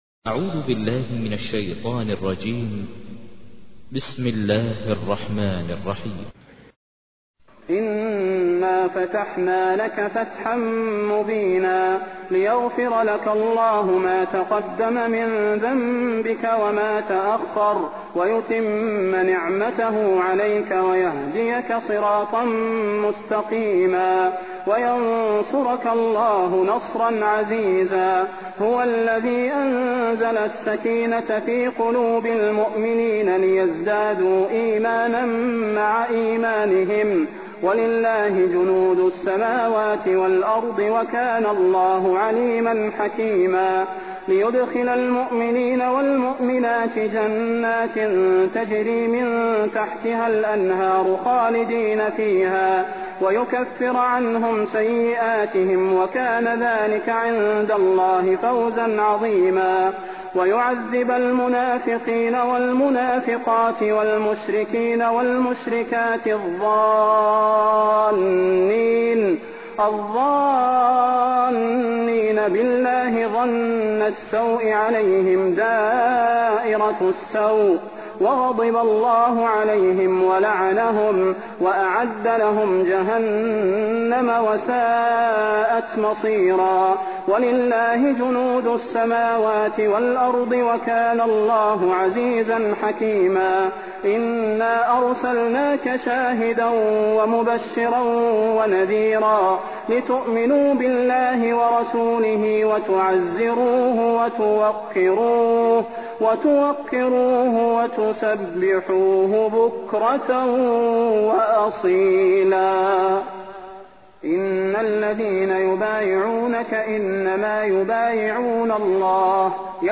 تحميل : 48. سورة الفتح / القارئ ماهر المعيقلي / القرآن الكريم / موقع يا حسين